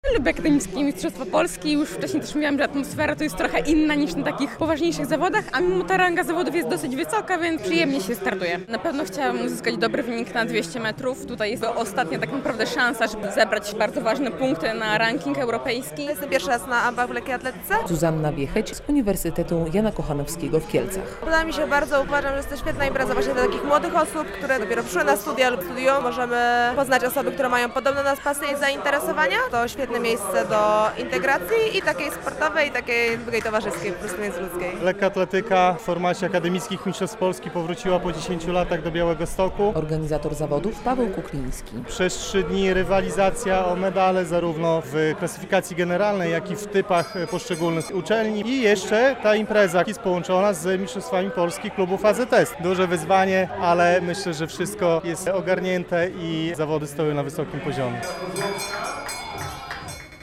Na stadionie przy ul. 11 Listopada w Białymstoku rozpoczęły się Akademickie Mistrzostwa Polski w lekkiej atletyce.
relacja